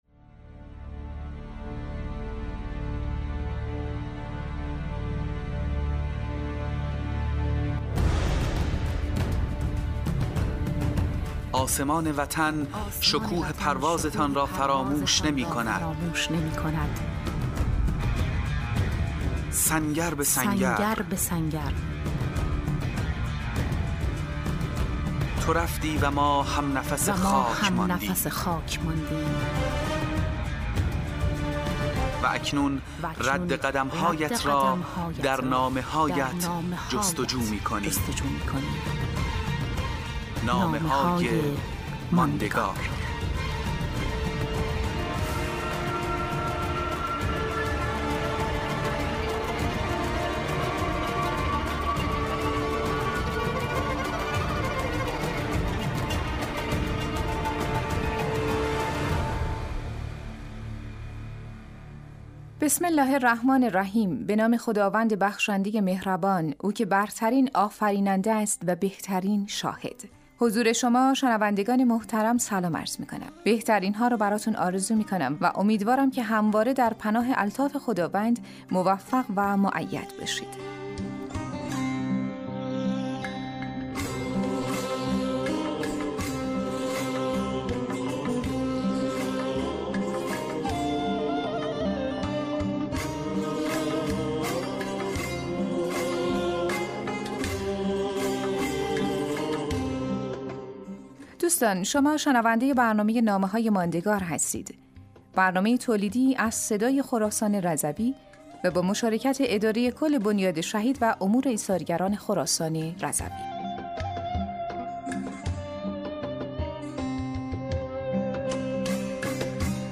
نوید شاهد : مجموعه 25 برنامه رادیویی با موضوع نامه های به یادگار مانده از شهدای استان حراسان رضوی